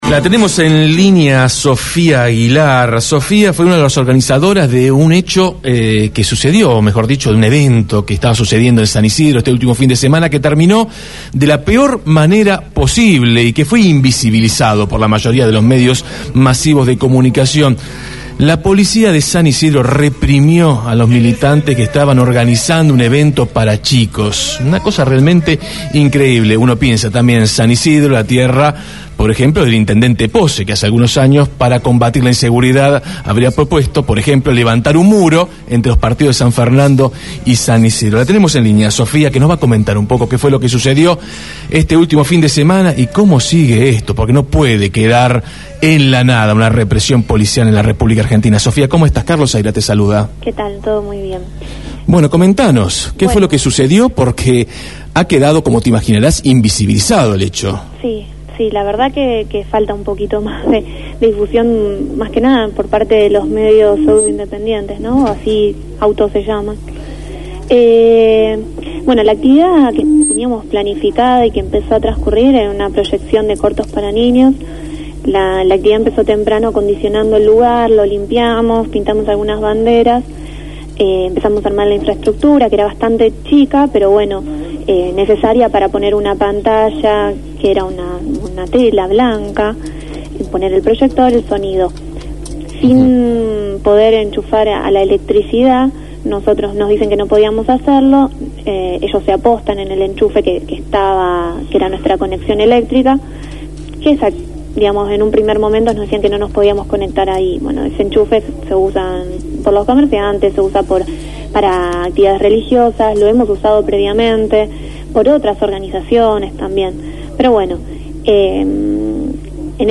dialogó en Desde el Barrio sobre lo ocurrido el sábado.